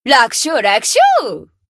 贡献 ） 分类:蔚蓝档案语音 协议:Copyright 您不可以覆盖此文件。
BA_V_Neru_Bunny_Battle_Victory_2.ogg